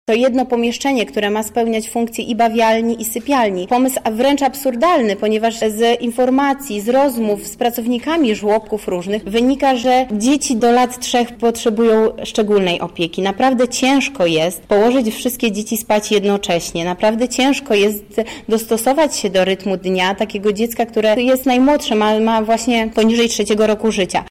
• mówi Beata Stepaniuk-Kuśmierzak, radna miasta Lublin